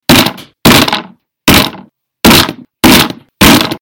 Download Wood sound effect for free.
Wood